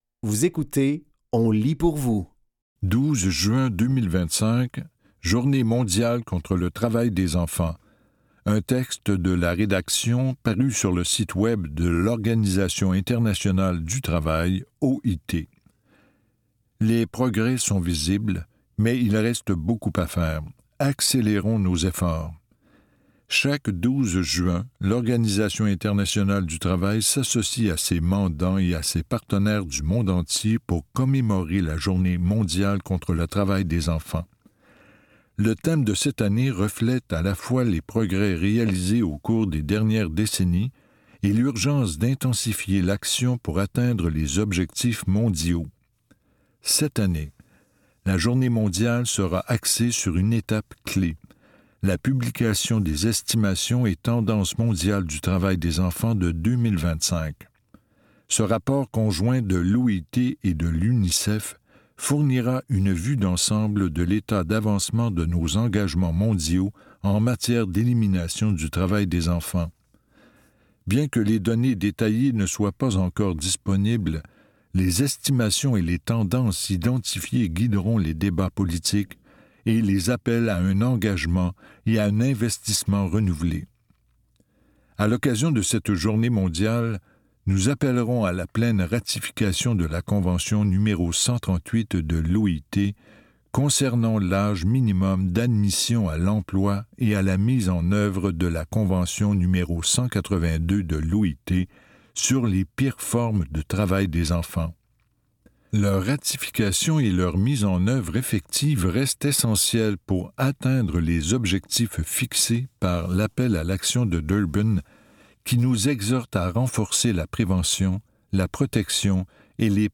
Dans cet épisode de On lit pour vous, nous vous offrons une sélection de textes tirés du média suivant : l'OIT et La Presse. Au programme: 12 juin 2025 – Journée mondiale contre le travail des enfants, un texte de la rédaction, paru sur le site de l'OIT.